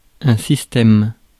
Ääntäminen
Tuntematon aksentti: IPA: /sis.tɛm/